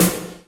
Snare - Roland TR 42